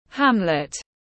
Hamlet /ˈhæm.lət/